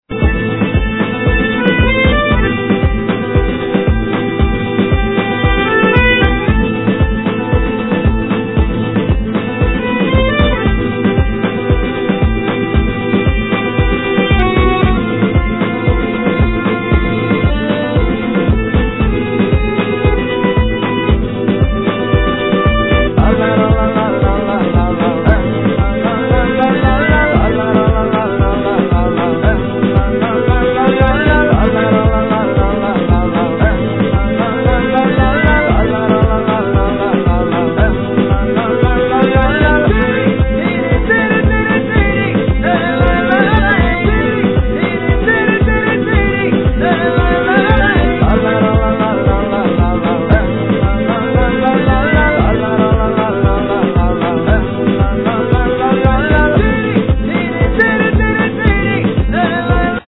Voice, Vocals, Percussions
Kementse, Violin
Clarinet, Zournas, Ney
Tzouras, Baglamas, Keyboards